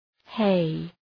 Προφορά
{heı}